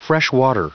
Prononciation du mot freshwater en anglais (fichier audio)
Prononciation du mot : freshwater